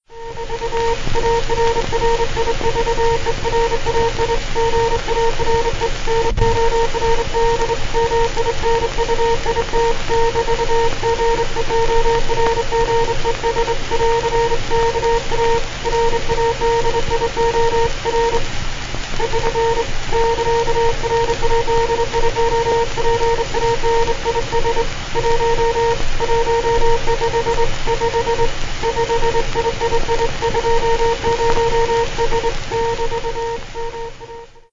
[CW] Fast Morse Hand Sending
Years ago I found some fast hand sending it must have been recorded
fast_hand_key2.mp3